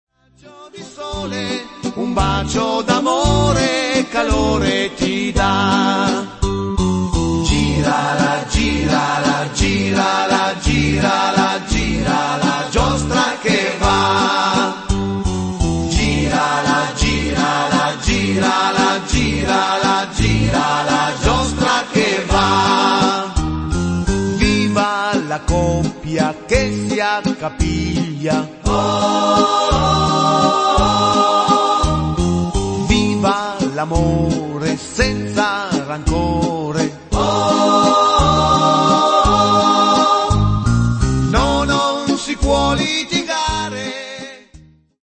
valzer